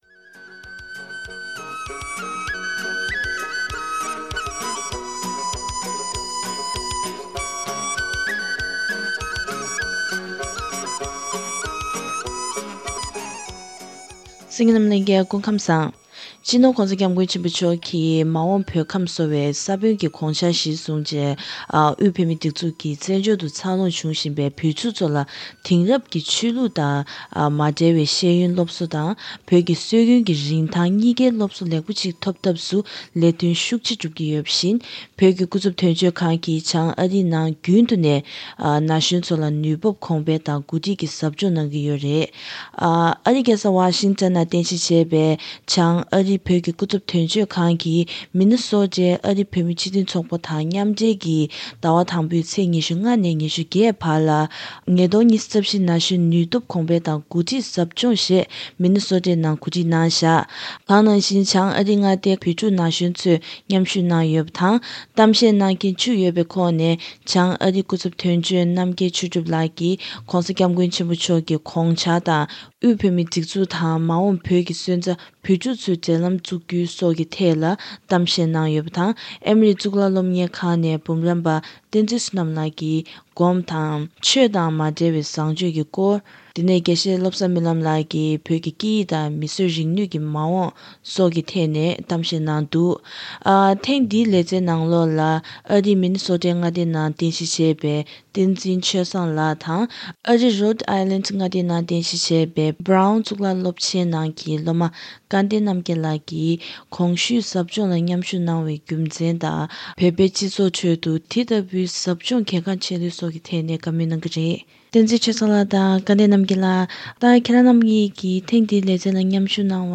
ཁོང་རྣམ་གཉིས་ལ་བཀའ་འདྲི་ཞུས་པ་ཞིག་གསན་རོགས་གནང་།